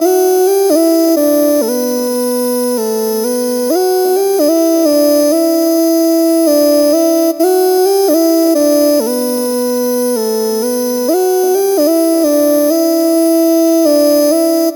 Samples für Urban Music
Es finden sich kurze Staccatos aus Hihats und Snares, diese saftigen, mit Fingerschnippen vermischten Claps und monophone Synthie-Sequenzen mit starkem Signal- und Sirenencharakter.
Bpm65_Em_Away_FizzleSynth_01.mp3